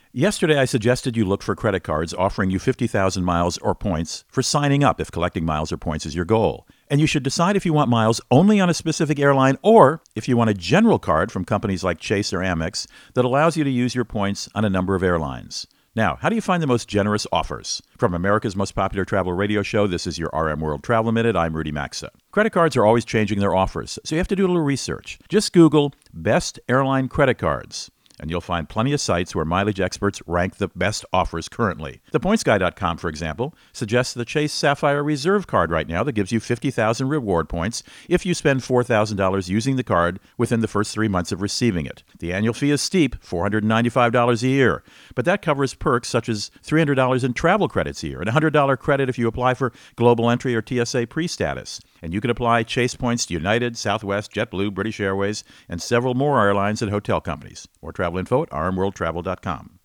Thursday, 14 February 2019 .. Co-Host Rudy Maxa | Maximizing Miles with Credit Cards